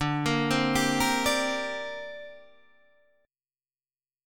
D7sus4#5 chord